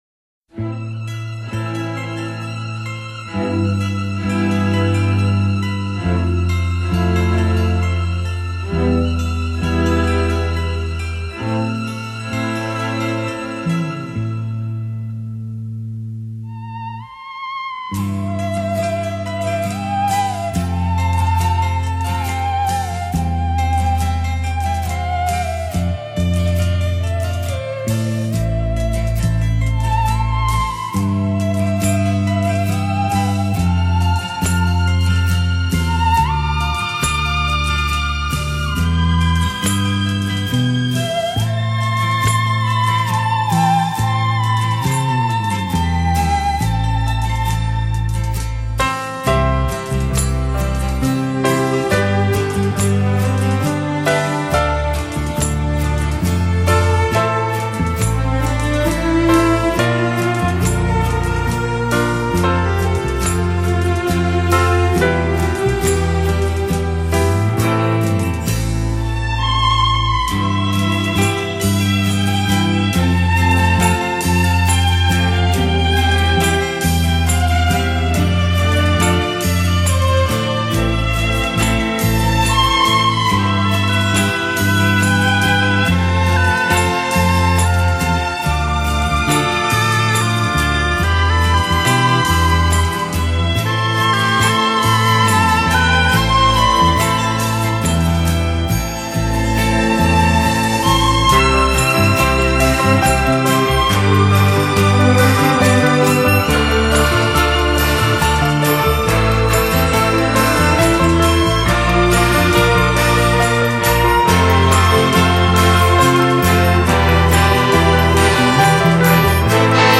情调音乐使者